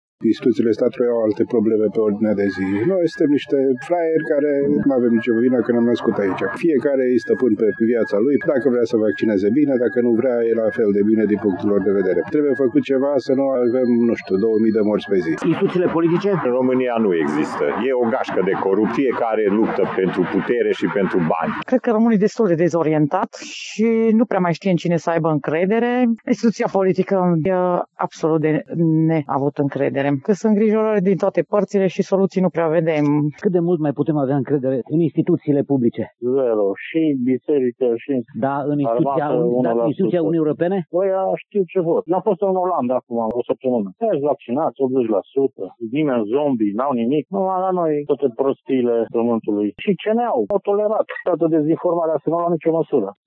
Opiniile mureșenilor sunt împărțite, nu toți au încredere în valorile europene, însă, fără excepție, toți condamnă clasa politică românească: